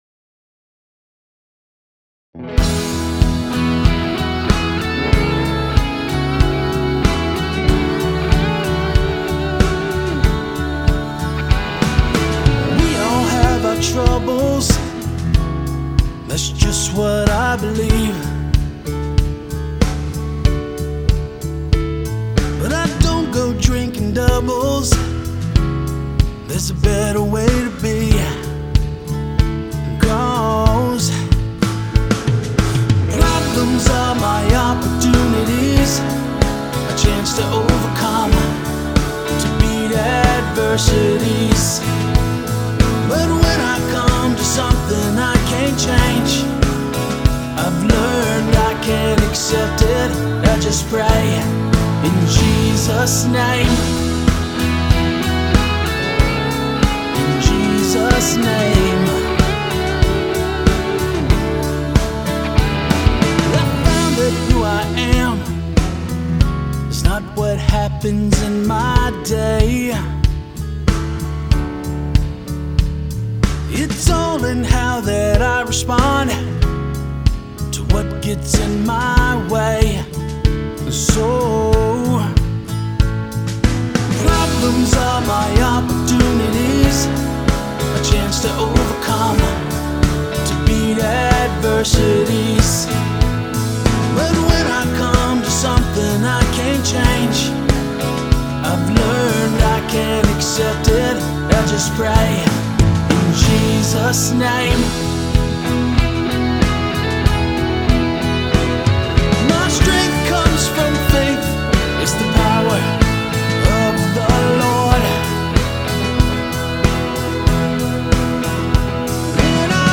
Complete Demo Song, with lyrics and music